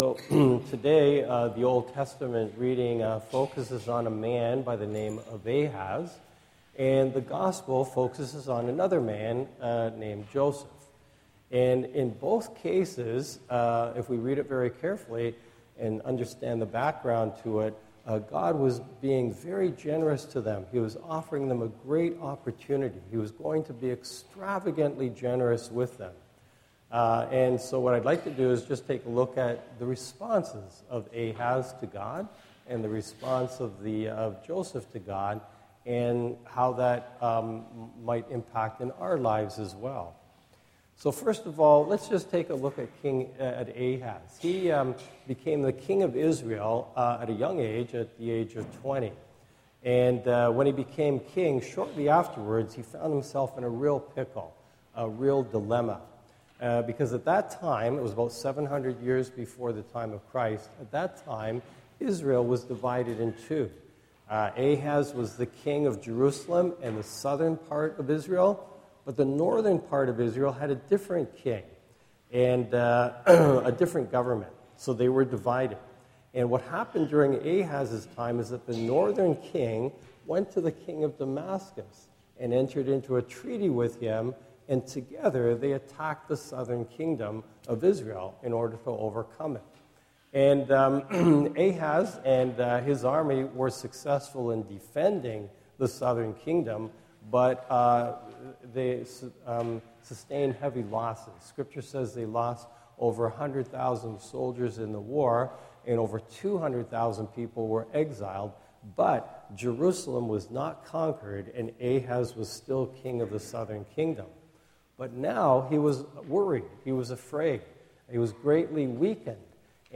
Homily
recorded at Holy Family Parish